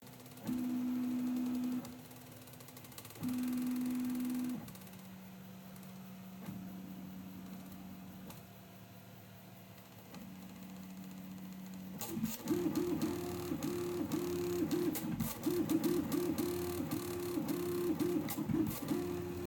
Filamentsensor knarzt
Was mich aber stört ist das meiner Meinung nach der Filamentsensor knarzt.